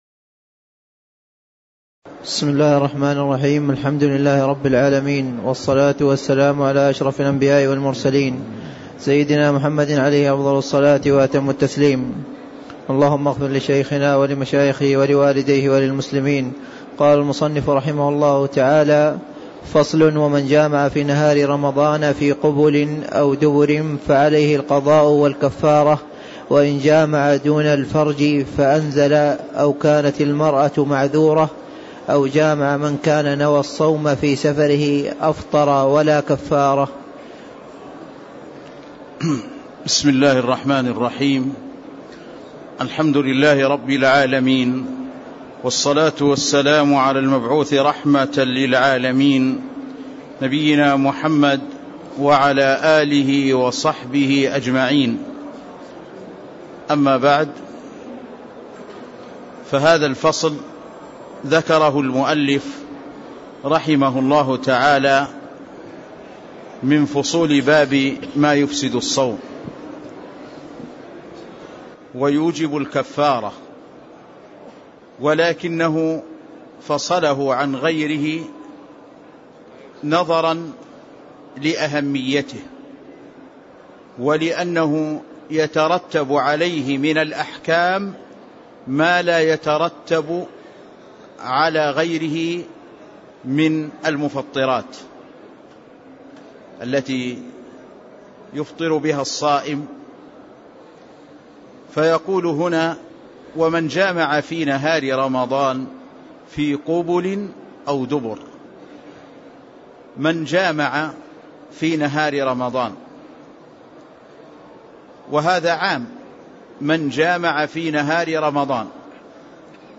تاريخ النشر ١٠ رمضان ١٤٣٦ هـ المكان: المسجد النبوي الشيخ